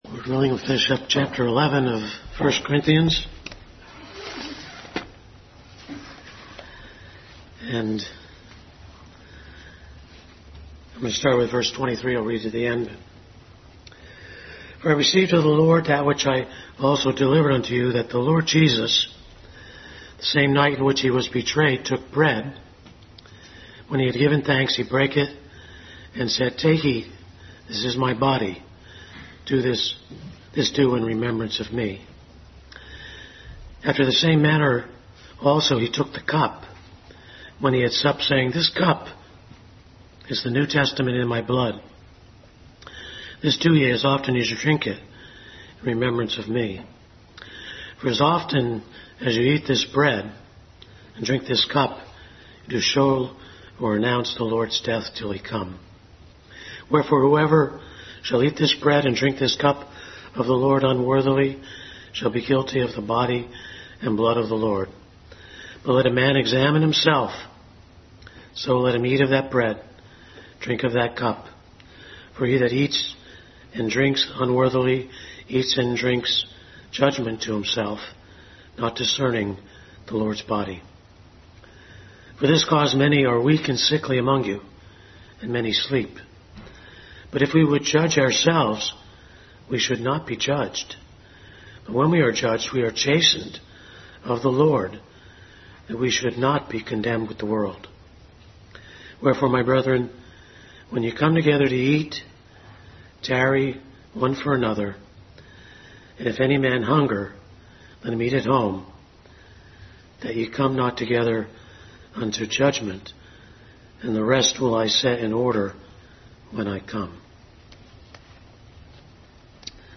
Adult Sunday School Class continued study in 1 Corinthians.